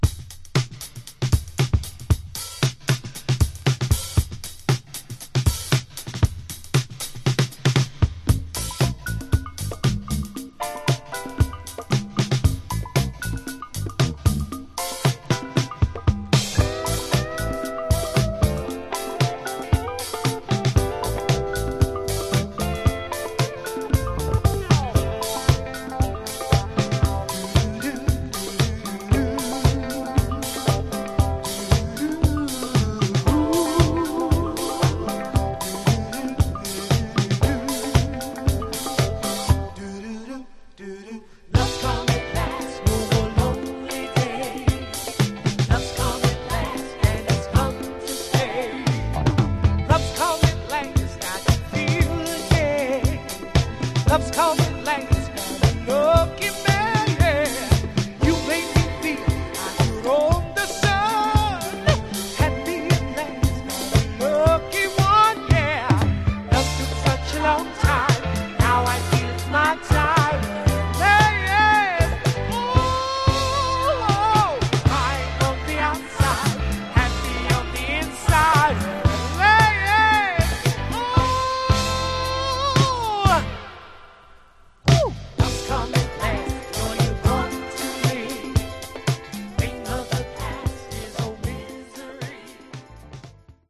It has pristine Mint sound.